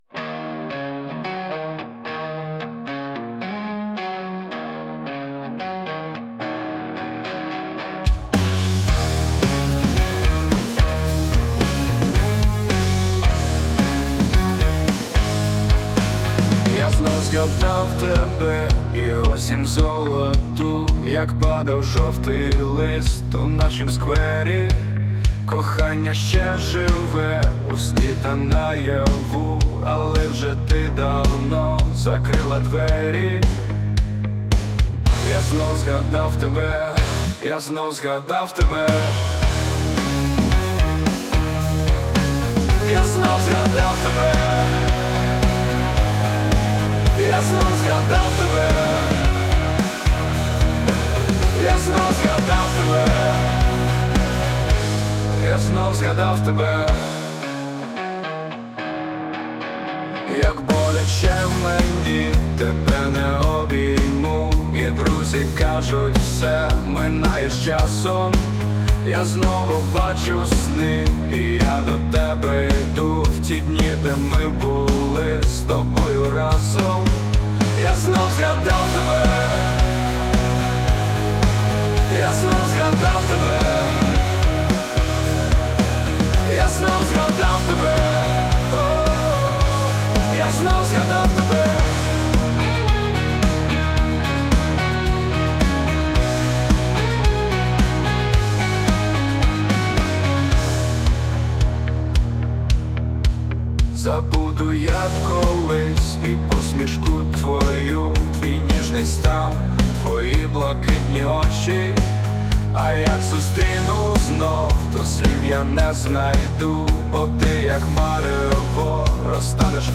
Музика та голос =SUNO
СТИЛЬОВІ ЖАНРИ: Ліричний
ВИД ТВОРУ: Пісня